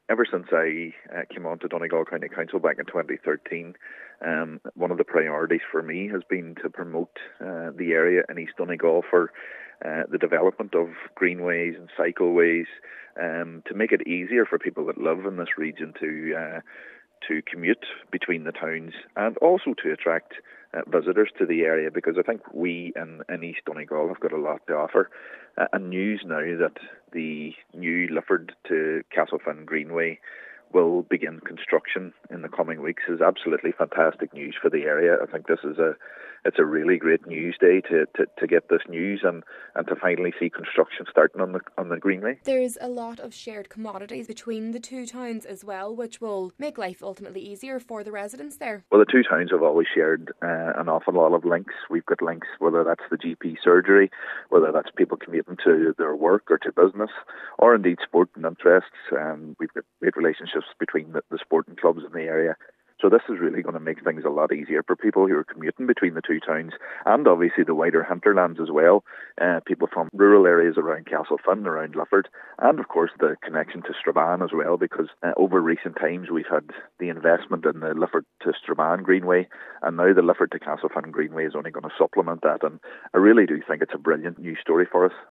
Cllr. Doherty says it will be useful to those living in rural parts of both towns: